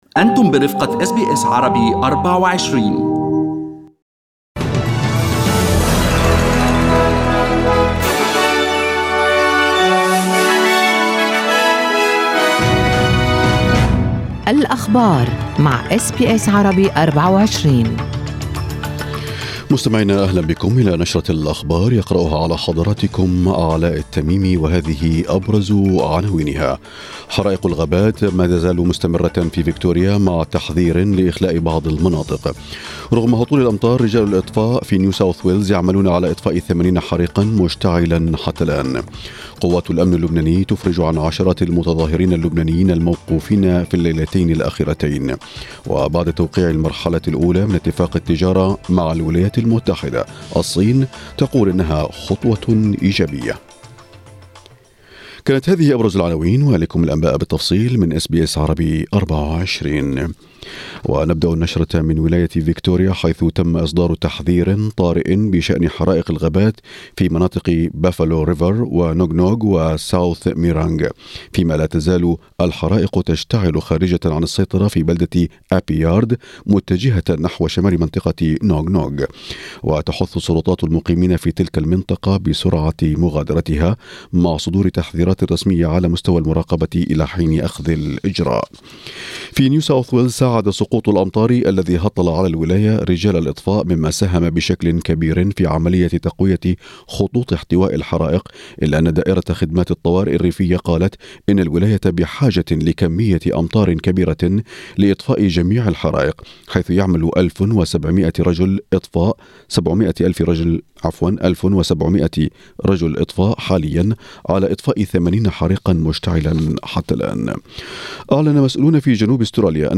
أخبار الصباح: الصين تصف اتفاق التجارة مع الولايات المتحدة "بالخطوة الإيجابية"